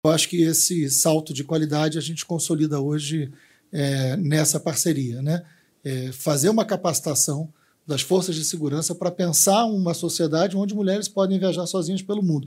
Presidente da Embratur, Marcelo Freixo, aponta parceria como um salto de qualidade na segurança turística.mp3 — Ministério da Justiça e Segurança Pública